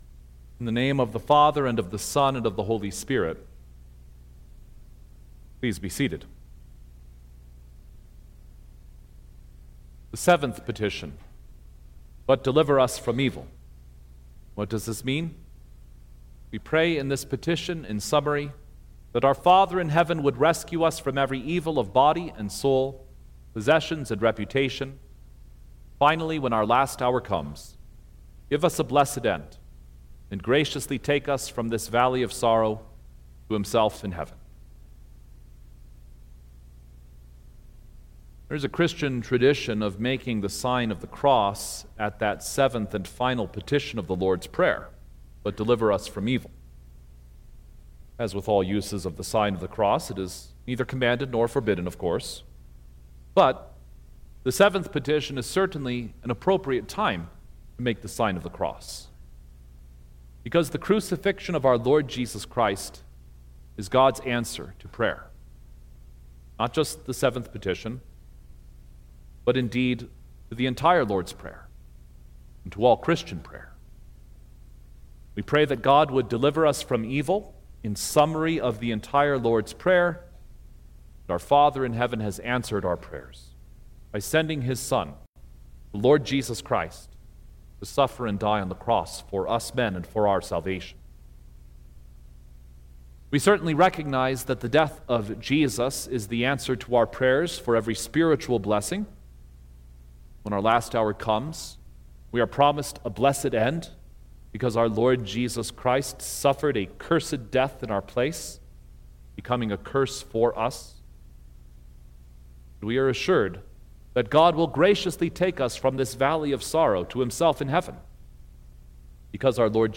April-3_2026_Good-Friday_Sermon-Stereo.mp3